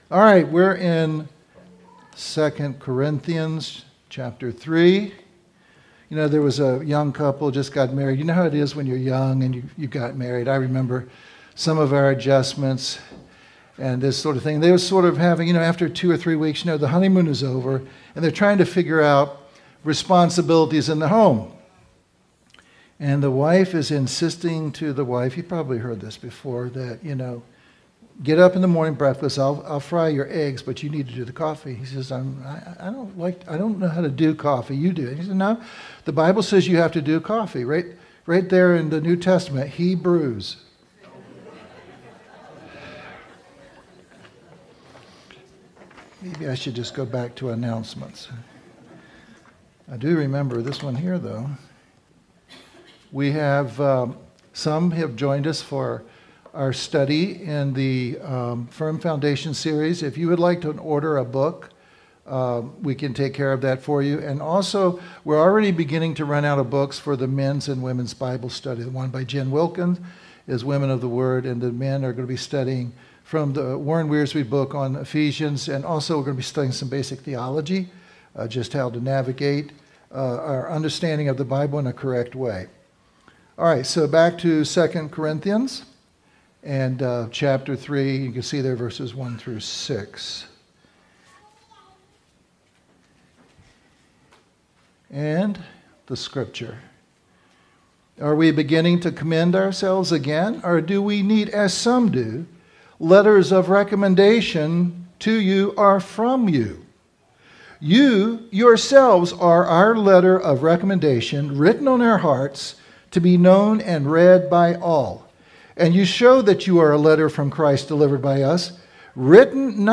Service Type: Regular Service